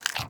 sounds / mob / panda / eat10.ogg
eat10.ogg